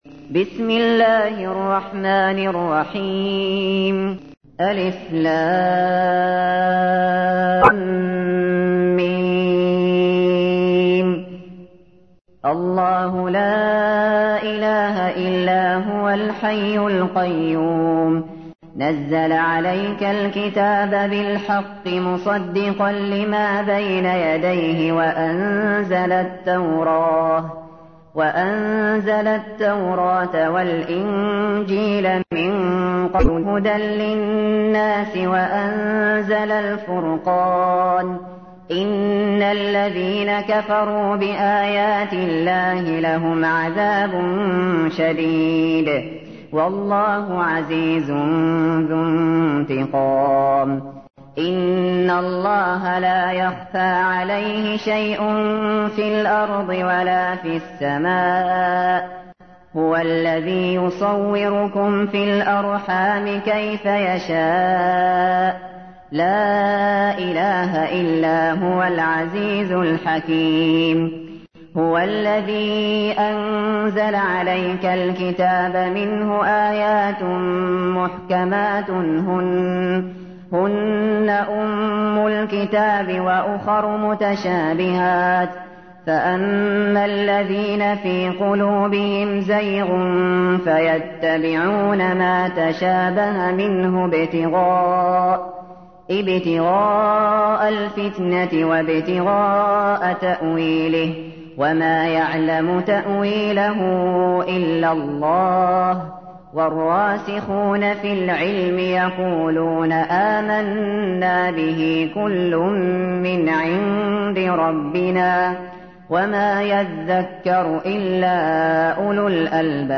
تحميل : 3. سورة آل عمران / القارئ الشاطري / القرآن الكريم / موقع يا حسين